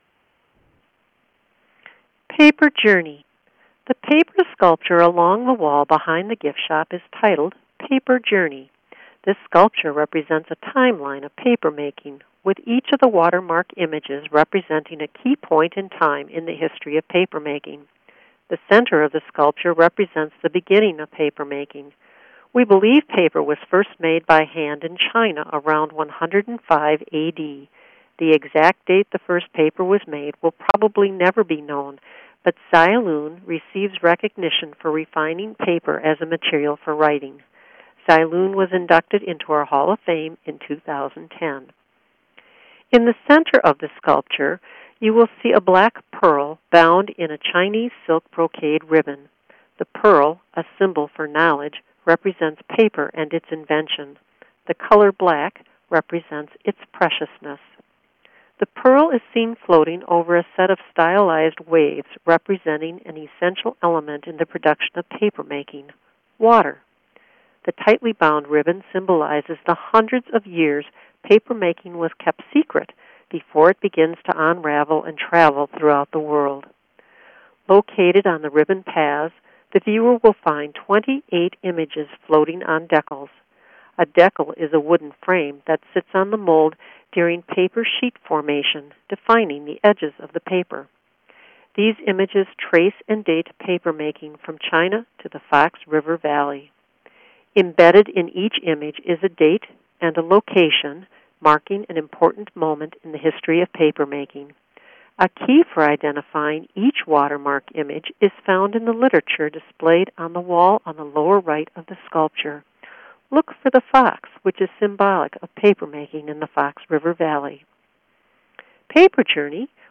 Audio Tour